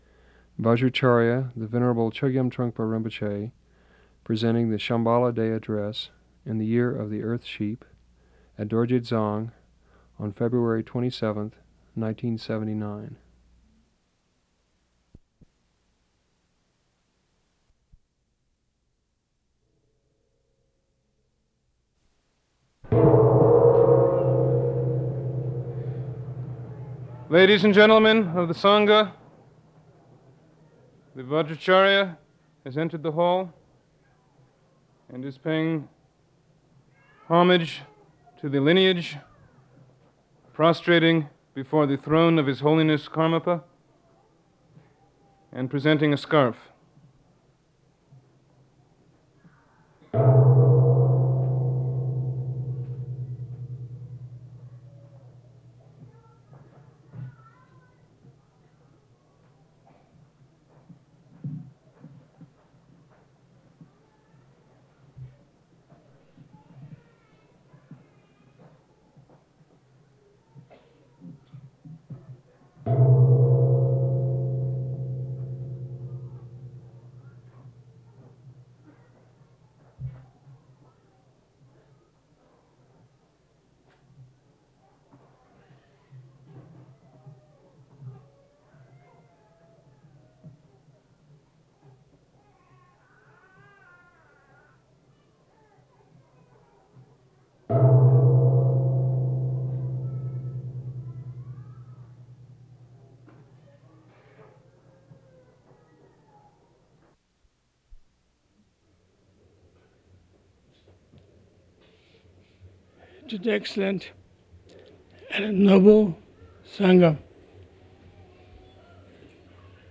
This talk took place very early in the morning on February 27, 1979 in the newly completed Karma Dzong shrine hall.